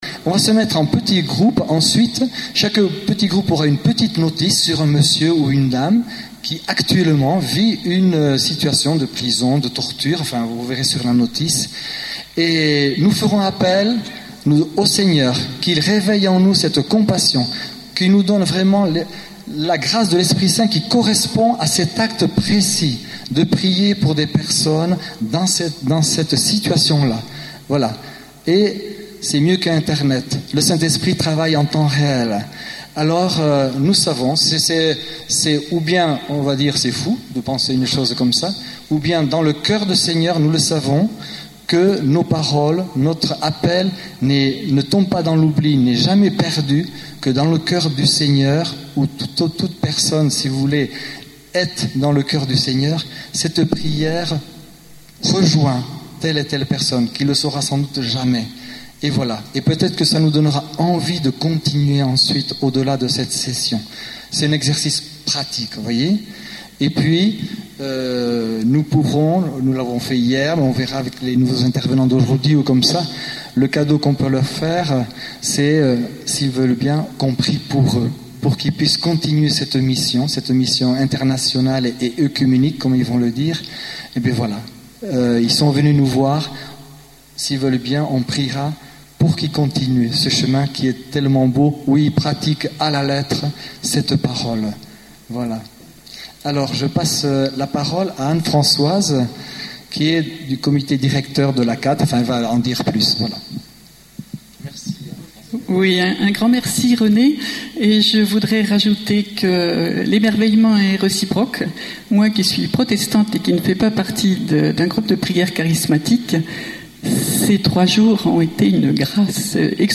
(Rassemblement � St Laurent sur S�vre organis� par la Fraternit� Pentec�te et plusieurs groupes de pri�re)
Mono